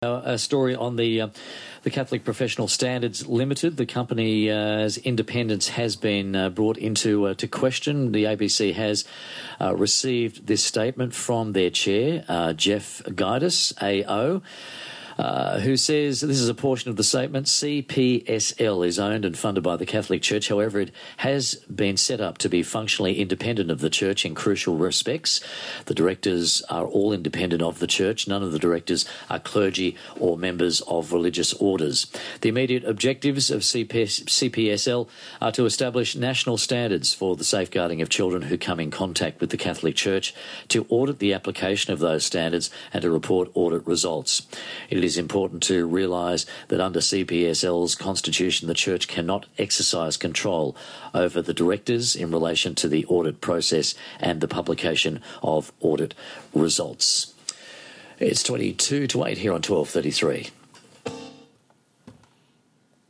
read the Statement to listeners on air on July 3.